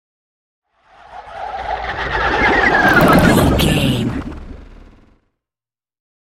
Scifi whoosh pass by bubbles
Sound Effects
bouncy
bright
futuristic